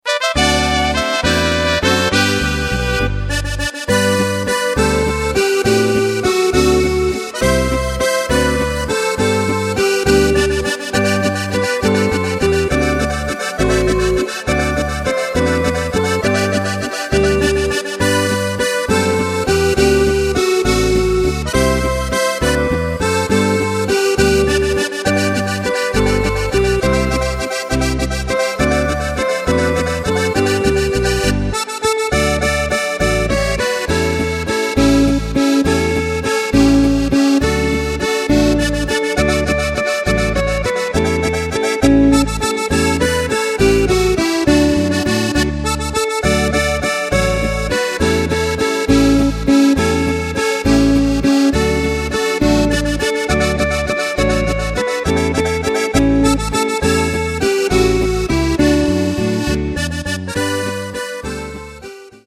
Takt:          3/4
Tempo:         204.00
Tonart:            G
Flotter Walzer zum Abendbeginn aus dem Jahr 1983!